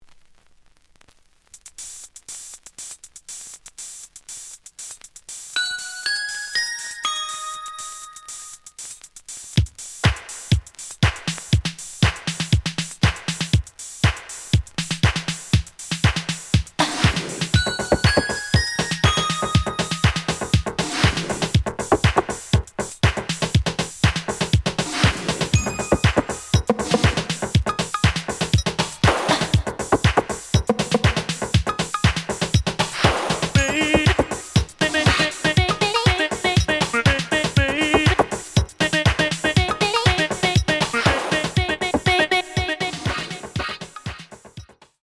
(7" Dub)
The audio sample is recorded from the actual item.
●Genre: Funk, 80's / 90's Funk